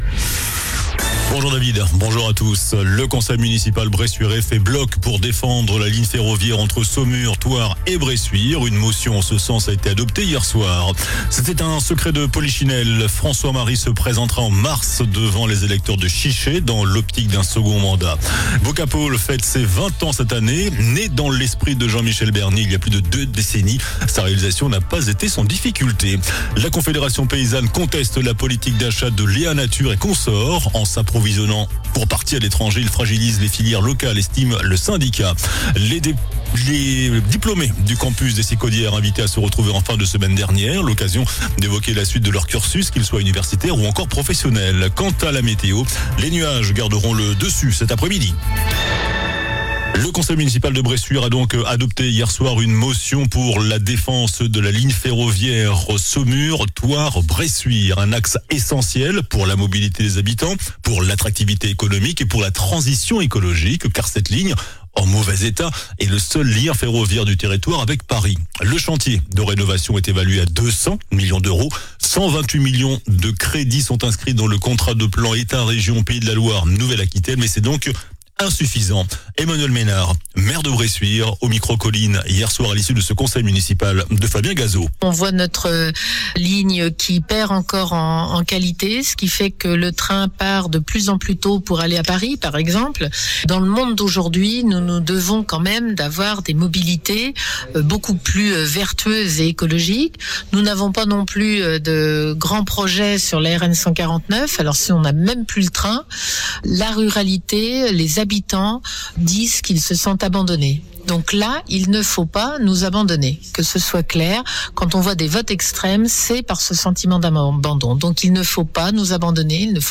JOURNAL DU MARDI 03 FEVRIER ( MIDI )